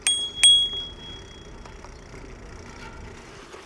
Bike Bell During Ride
bell bicycle bike bike-ride ding riding ring sound effect free sound royalty free Sound Effects